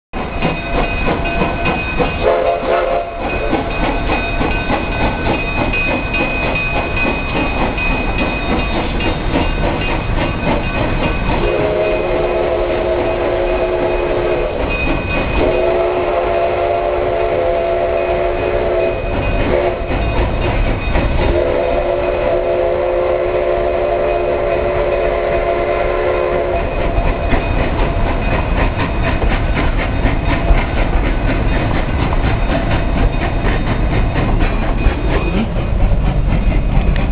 Audio recording of train from the window of our hotel room in the morning.